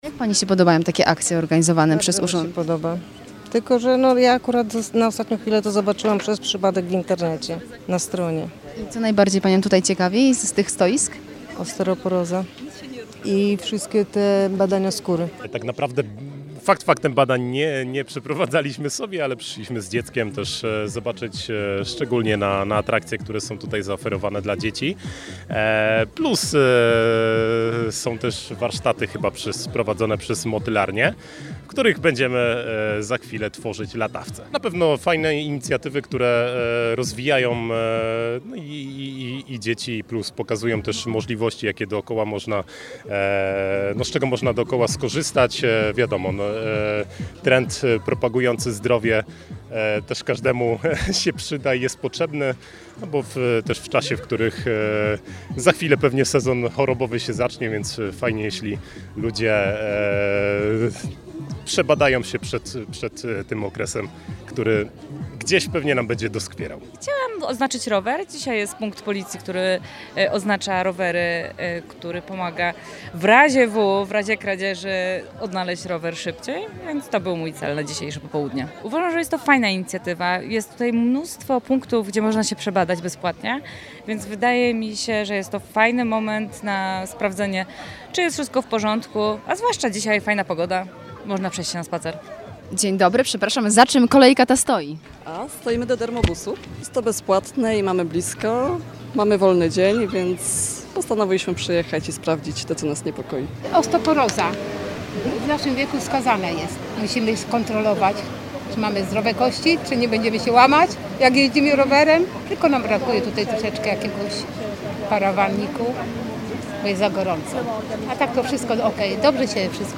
Zdrowa Aktywna Długołęka [relacja z wydarzenia]